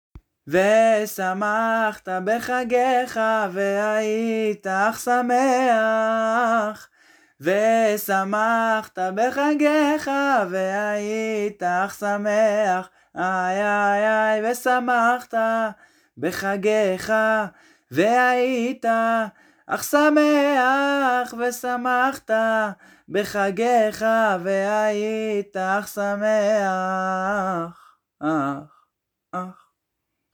וכו' חשוב לי לדעת (כמובן שזו הקלטה בסיסית מאוד)